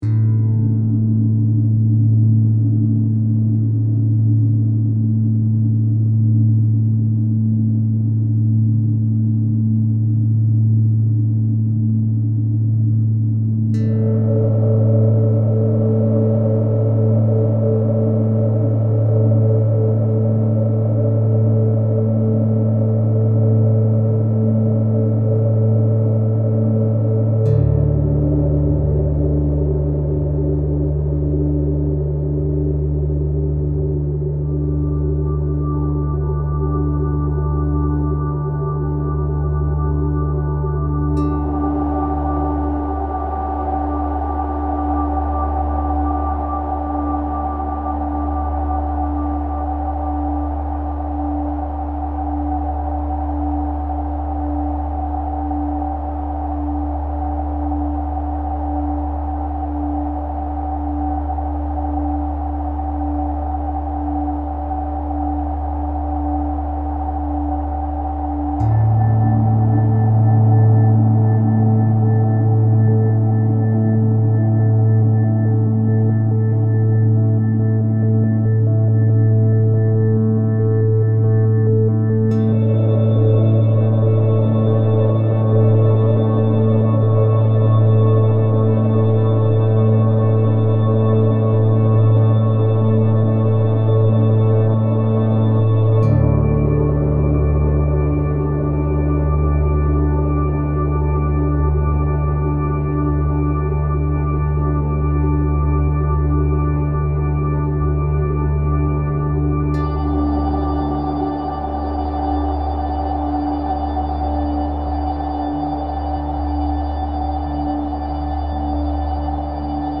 Recollections, remastered and reworked.
Overall? Dreamy.